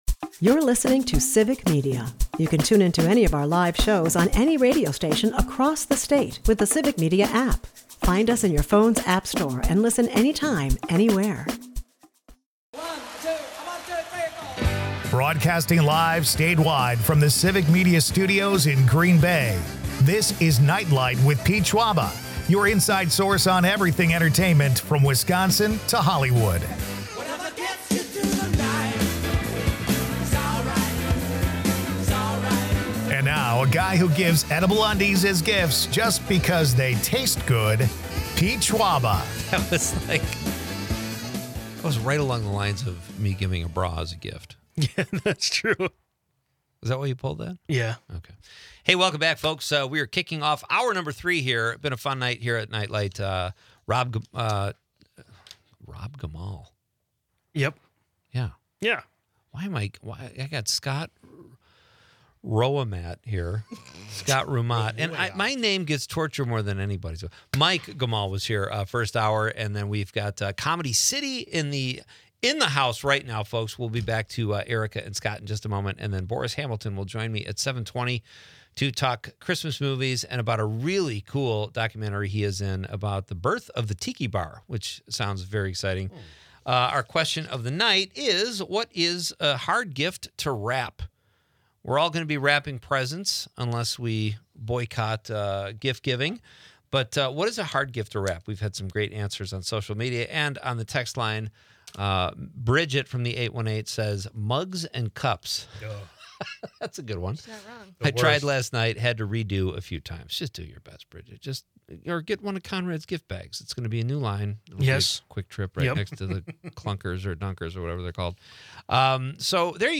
improv comedy